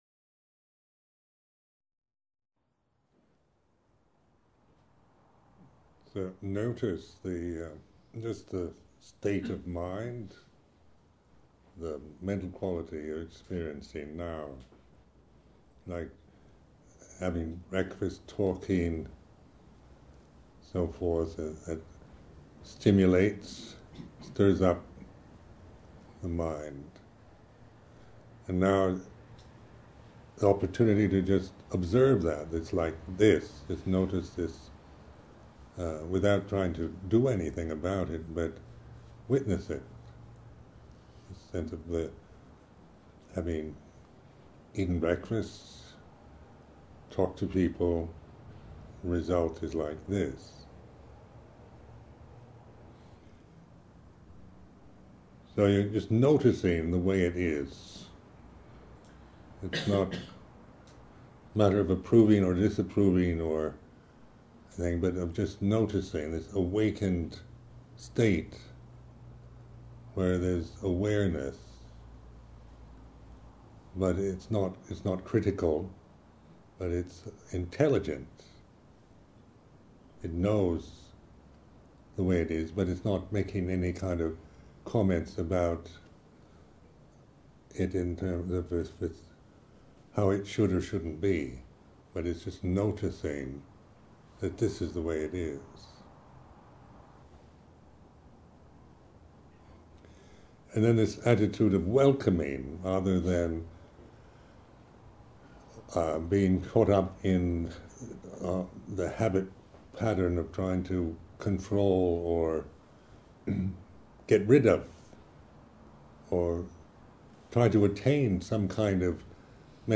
A talk by Ajahn Sumedho.
A talk given at the 2001 Buddhist Publishing Group Summer School.